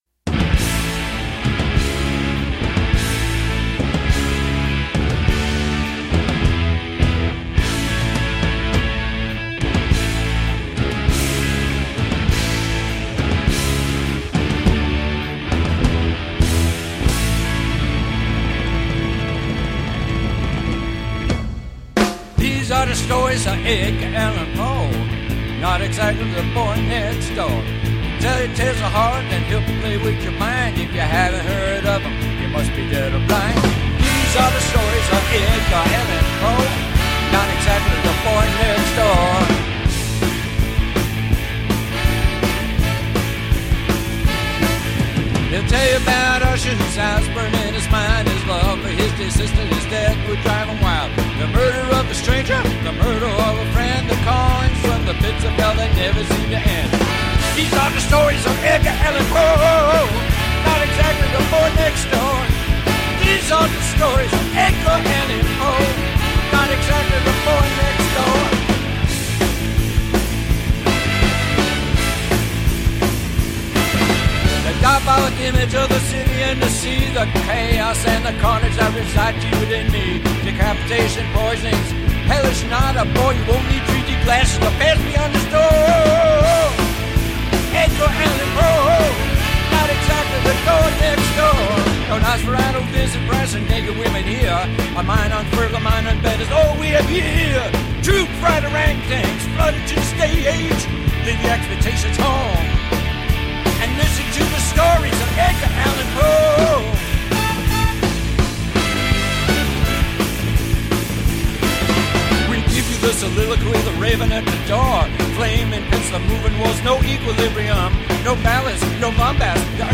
Radio Theatre: Edgar Allan Poe (Audio)